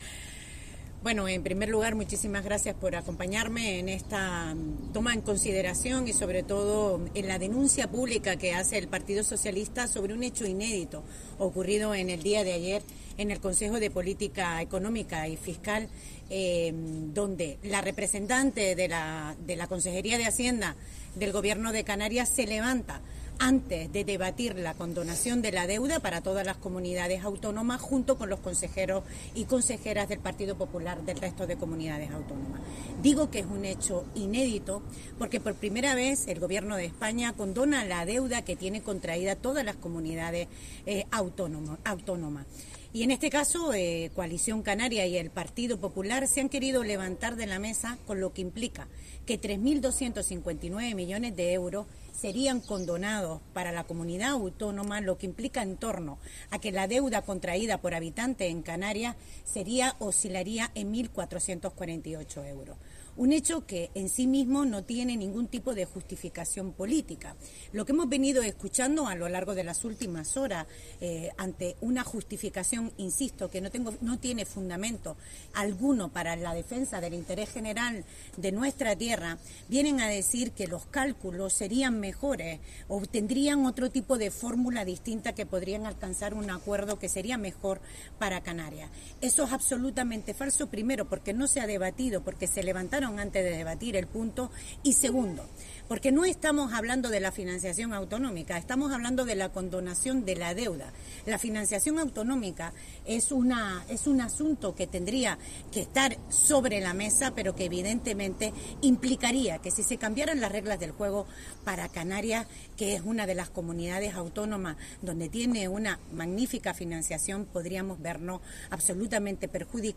La diputada y portavoz de los socialistas canarios en las Cortes Generales, Dolores Corujo, ha criticado este jueves en Arrecife (Lanzarote) el comportamiento del Partido Popular en la mesa de negociación de la quita de la deuda autonómica y ha lamentado que, en el caso de Canarias, donde gobiernan, sean capaces de dar las espaldas a unos recursos económicos tan importantes para los servicios esenciales simplemente por intereses partidistas.